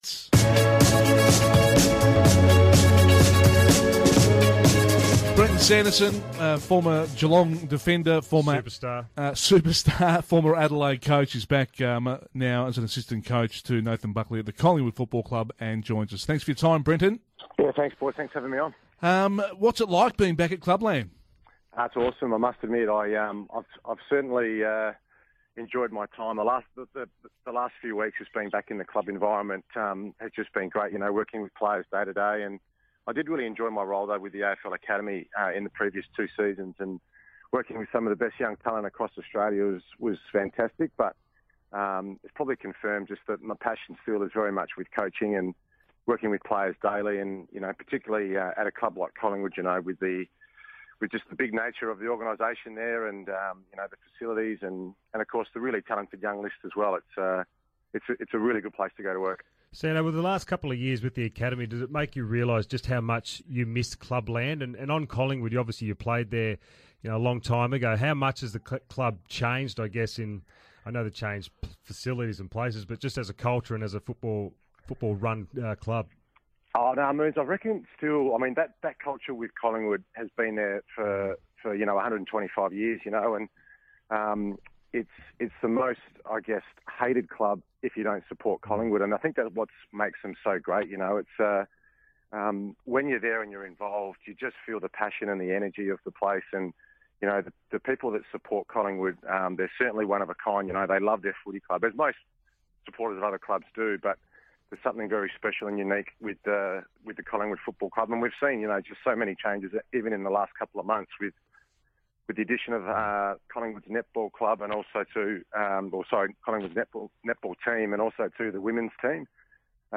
Listen to assistant coach Brenton Sanderson speak on SEN's Breakfast program.